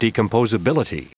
Prononciation du mot decomposability en anglais (fichier audio)